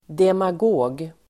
Uttal: [demag'å:g]